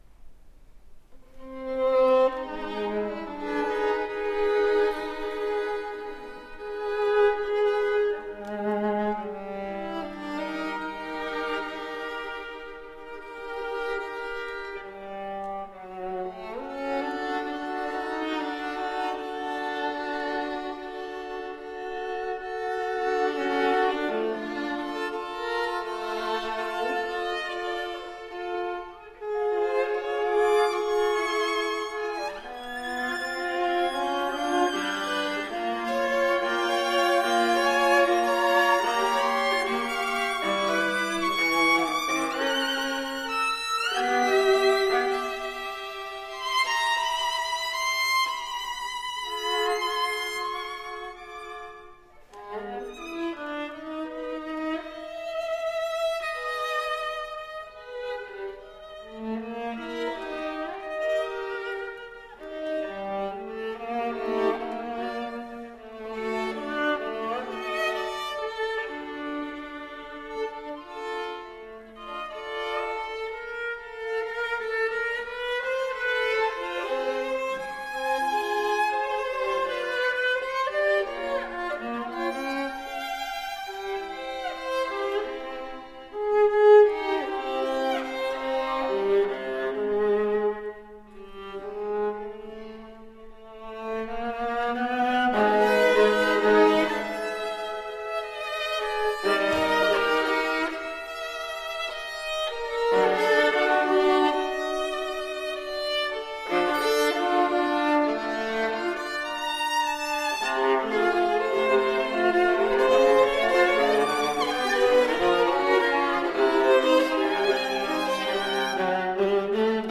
Artist Faculty Concert recordings - July 9, 2014 | Green Mountain Chamber Music Festival
violin
viola